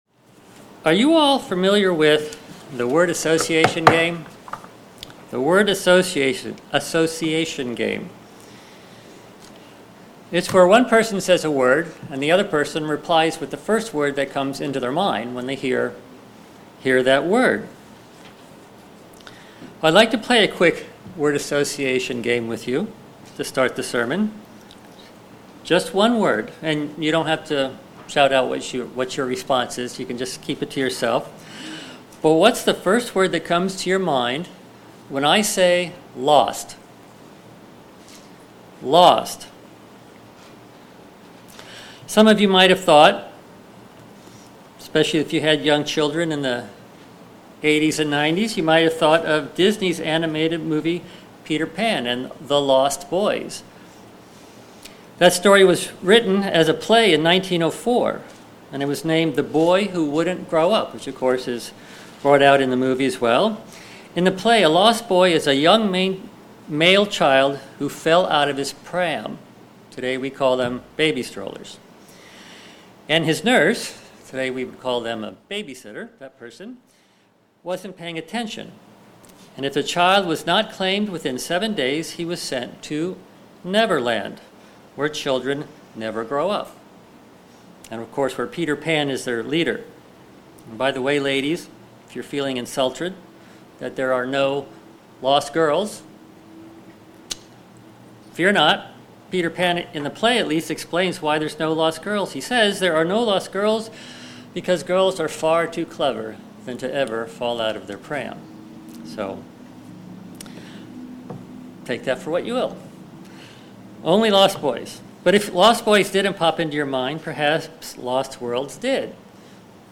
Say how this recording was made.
Given in Delmarva, DE